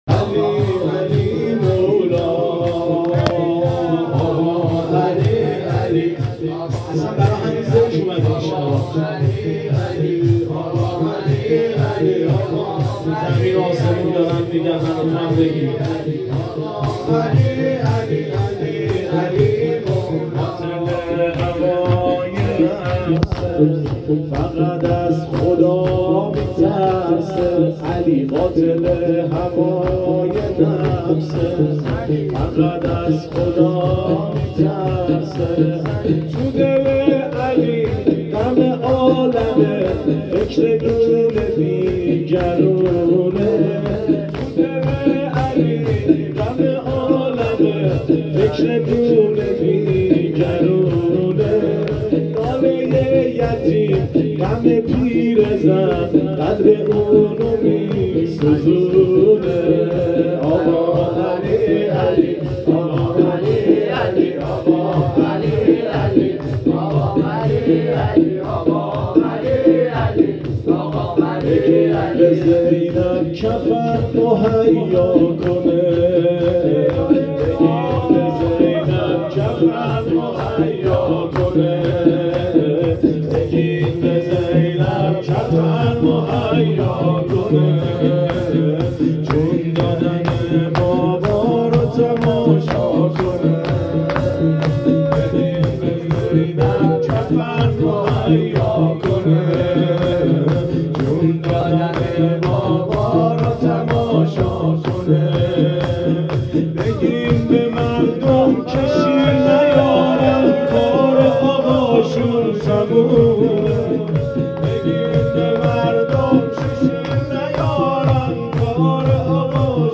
شبهای قدر ۱۴۰۲